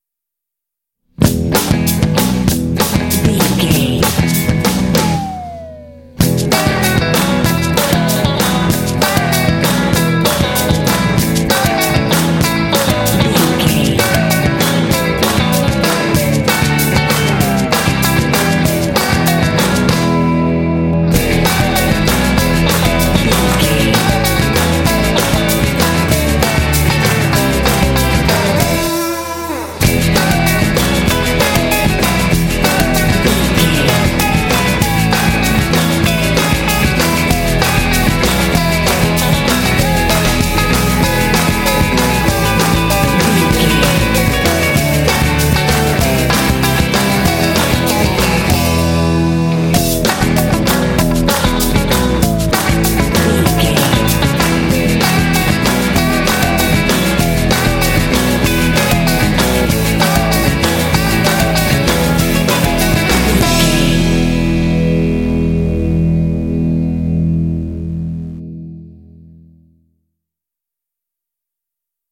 Uplifting
Ionian/Major
bouncy
happy
electric guitar
drums
bass guitar
surf